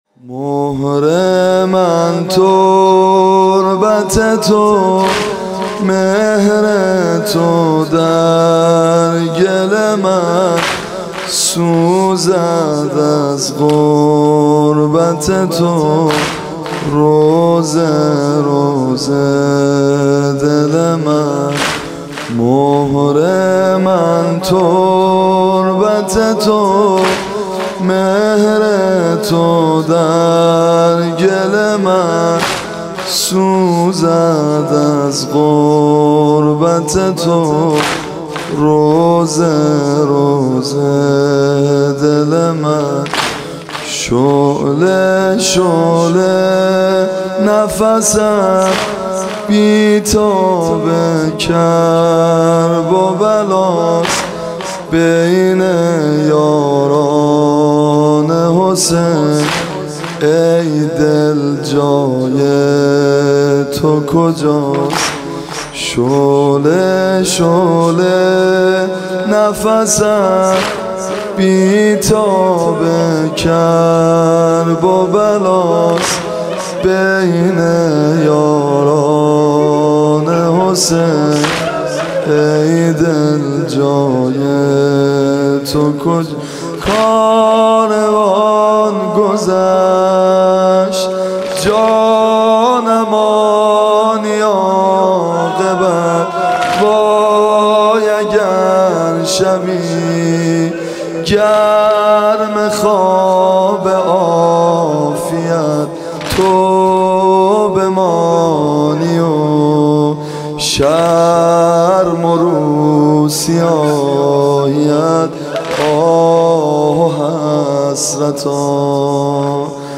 مراسم عزاداری سید و سالار شهیدان حضرت ابا عبدالله الحسین علیه السلام و یاران باوفایش در شام غریبان حسینی
مداحی